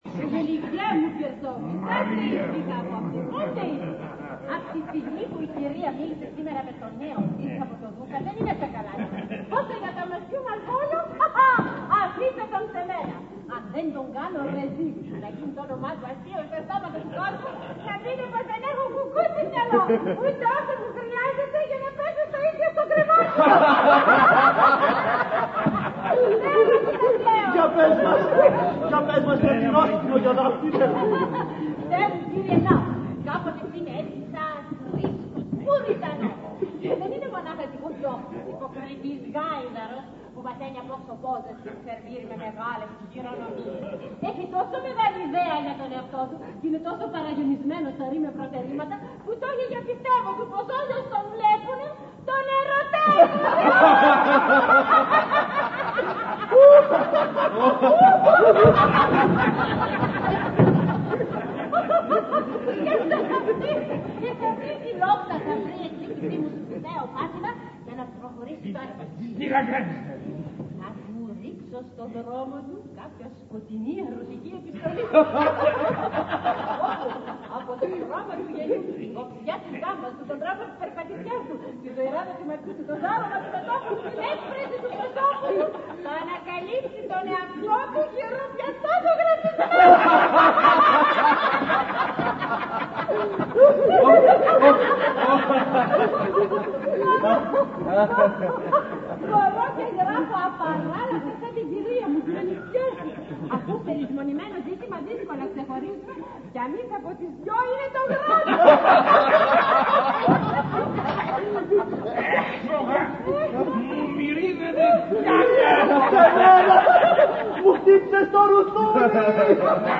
Ηχογράφηση Παράστασης
Αποσπάσματα από την παράσταση